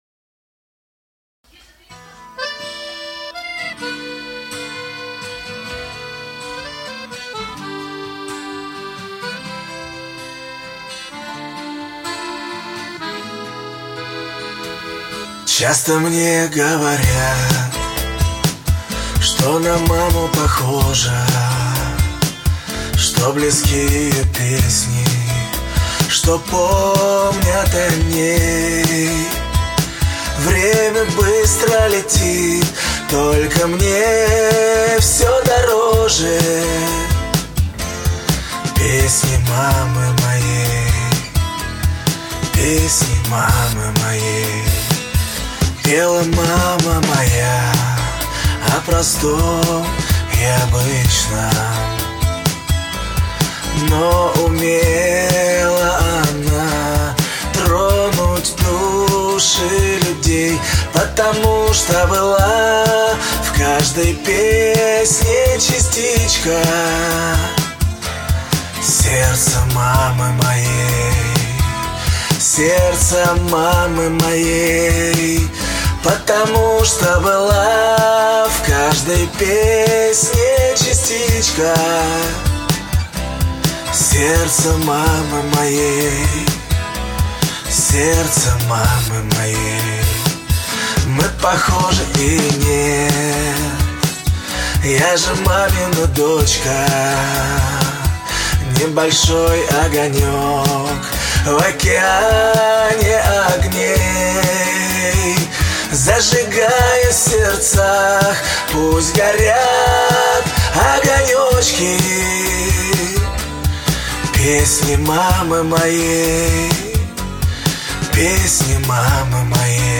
В статью добавлена ссылка на аудиодемо с переработанной мелодией.
А так конечно чувствуется при прослушивании, что мелодия получилась очень вымученная во втором варианте, а в первом — её вообще как бы нет.